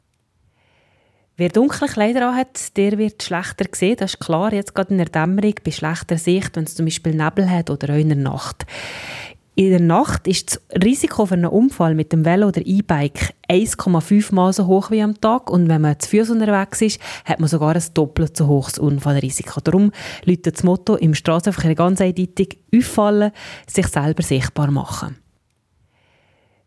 Die BFU bietet als Service einige Aussagen der Medienmitteilung als O-Ton zum Download an.